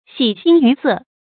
注音：ㄒㄧˇ ㄒㄧㄥˊ ㄧㄩˊ ㄙㄜˋ
喜形于色的讀法